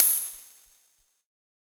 UHH_ElectroHatC_Hit-14.wav